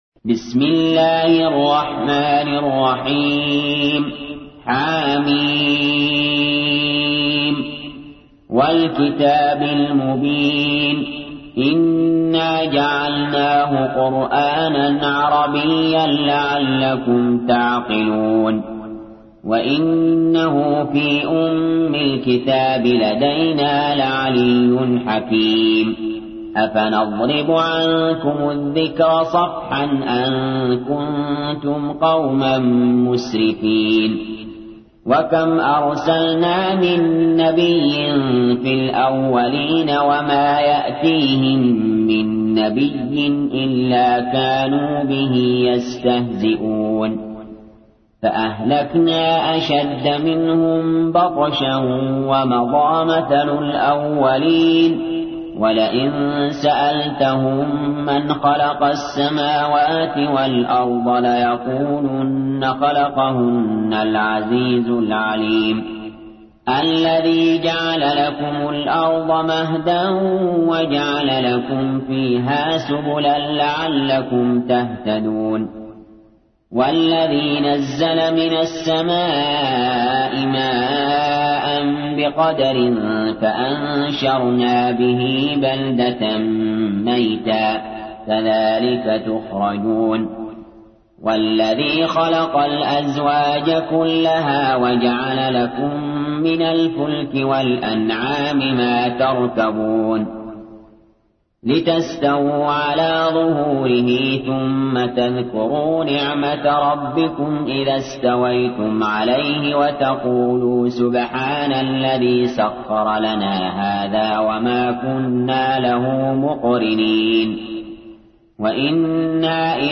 تحميل : 43. سورة الزخرف / القارئ علي جابر / القرآن الكريم / موقع يا حسين